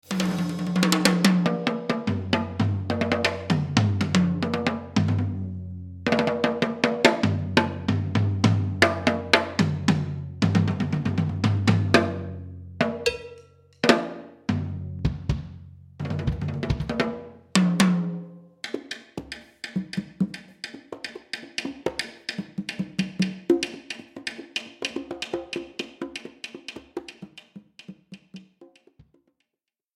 Voicing: Drum Set